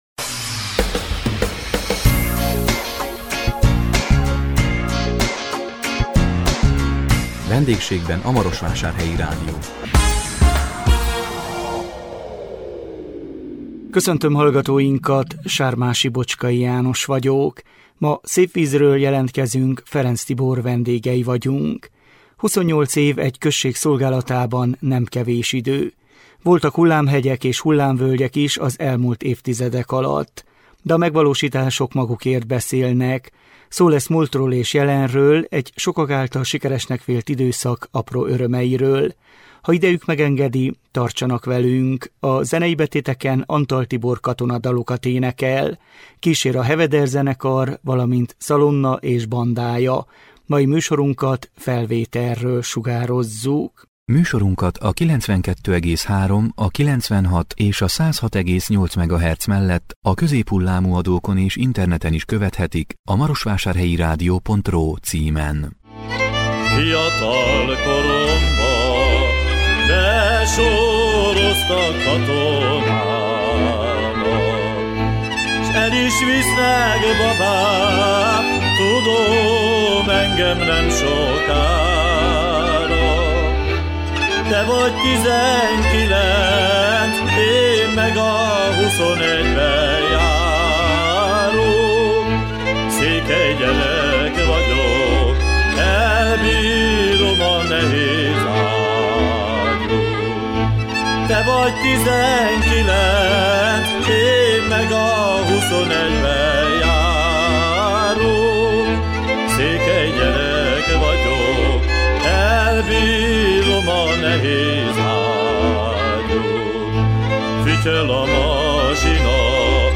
A 2025 február 6-án közvetített VENDÉGSÉGBEN A MAROSVÁSÁRHELYI RÁDIÓ című műsorunkkal Szépvízről jelentkezünk, Ferencz Tibor vendégei voltunk. 28 év egy község szolgálatában nem kevés idő.